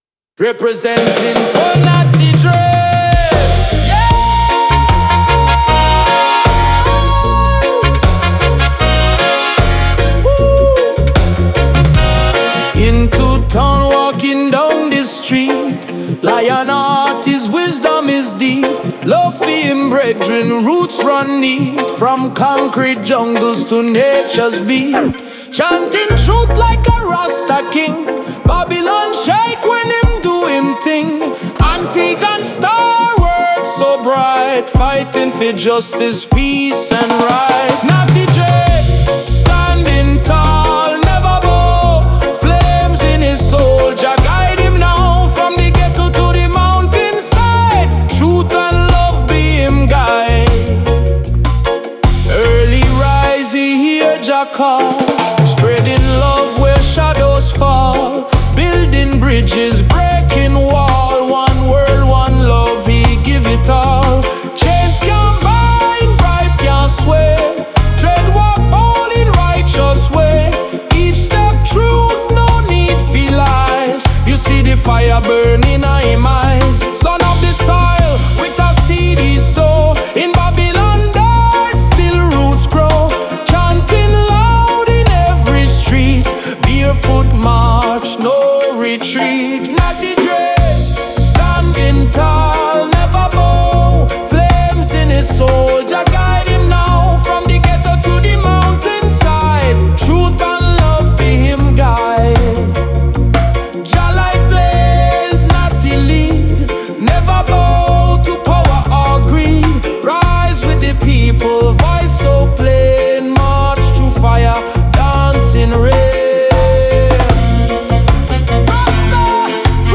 Musically bright with horns and guitar chops